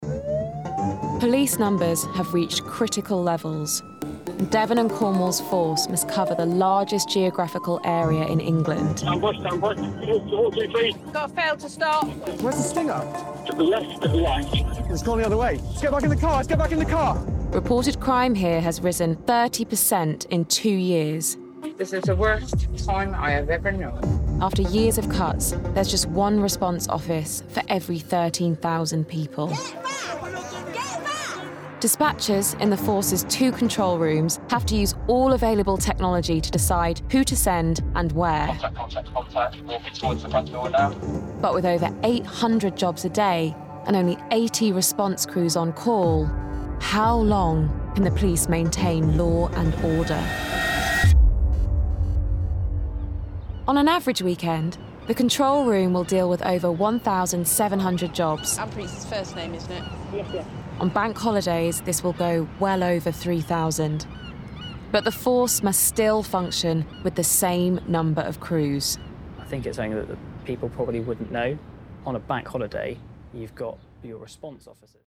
20/30's Northern/Neutral,
Contemporary/Relaxed/Cool
Commercial Showreel Vodafone Macmillan Cancer Support (Northern) Volkswagen Visa Thorntons Google (US accent)